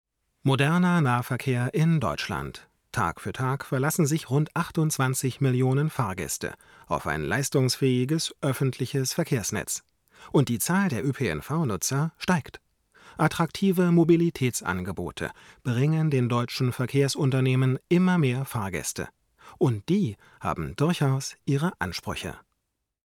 Nun habe ich noch mal ein wenig probiert: Jetzt gibt es eine Absenkung von etwa 2 dB bei etwa 120 Hz, eine Absenkung von etwa 2 dB bei etwa 1,2 kHz und eine Absenkung von etwa 3 dB bei etwa 5 kHz. Dann noch eine winzige Anhebung bei 8 kHz.
Das klingt jetzt meiner Meinung nach ziemlich rund und weder spitz, noch schreiend oder nervend.